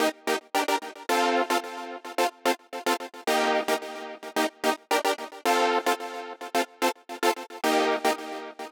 30 Synth PT2.wav